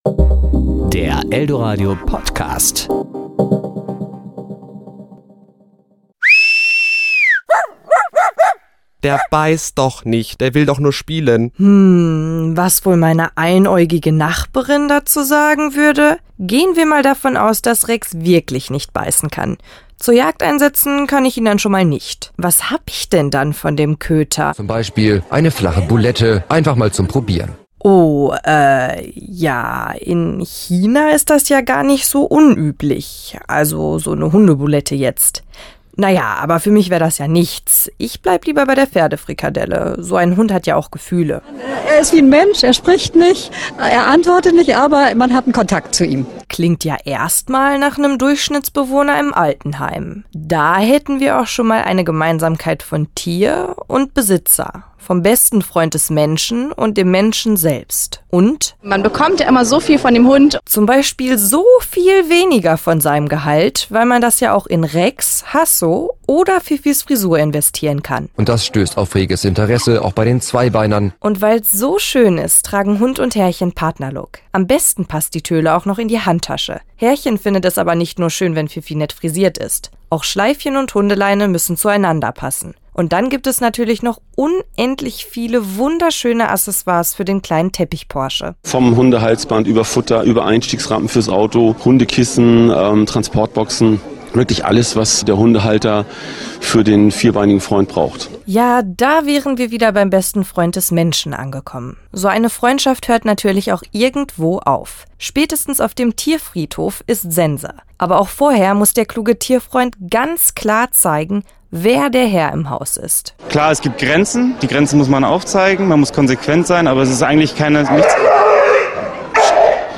Glosse Hunde und Pferd
Glosse  Ressort: Comedy  Sendung